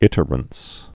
(ĭtər-əns)